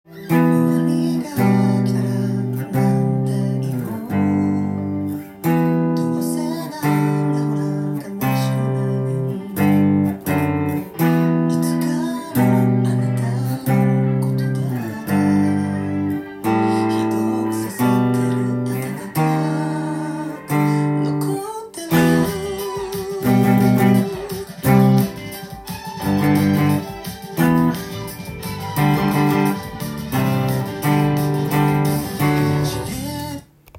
音源に合わせて譜面通り弾いてみました
弾けるようにパワーコードでTAB譜にしてみました。
keyがGですが、たまにD♯なども出てきます。